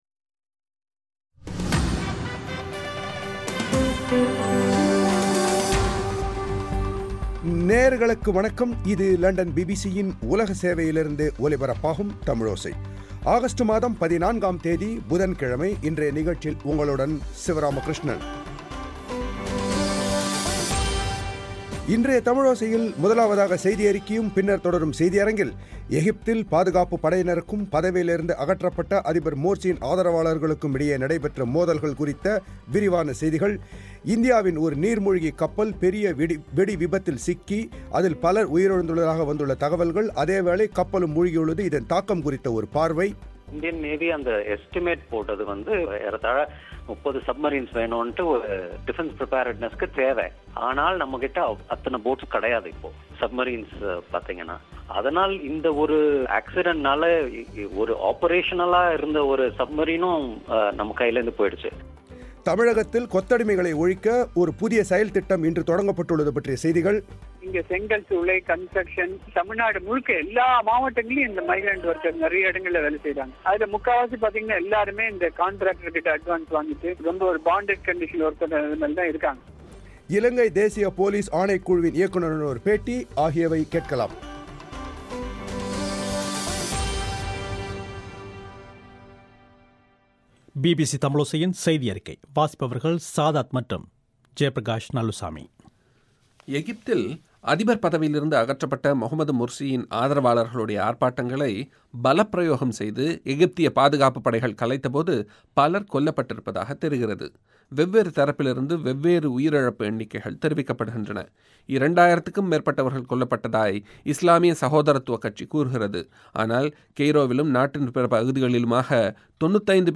எகிப்தில் பாதுகாப்பு படையினருக்கும், பதவியிலிருந்து அகற்றப்பட்ட அதிபர் மோர்ஸியின் ஆதரவாளர்களுக்கும் இடையே நடைபெற்ற மோதல்கள் குறித்த விரிவான செய்திகள்.